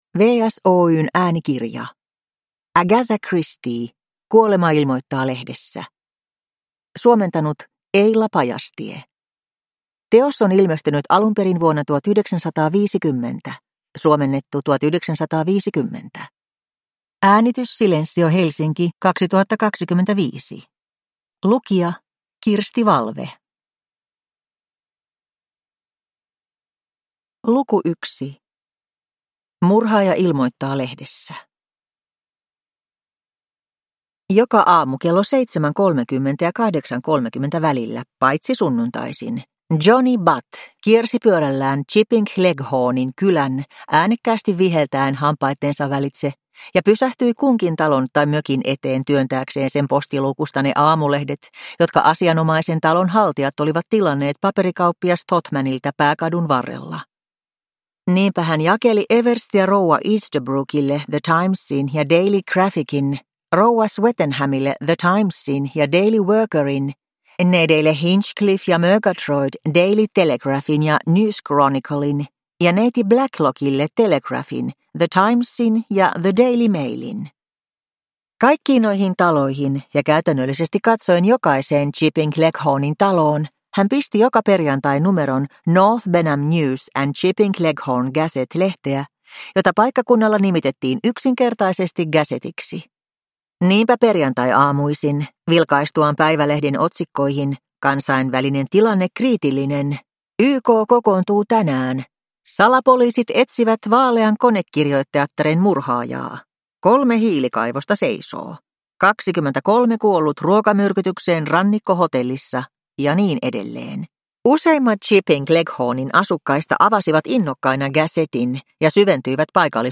Kuolema ilmoittaa lehdessä – Ljudbok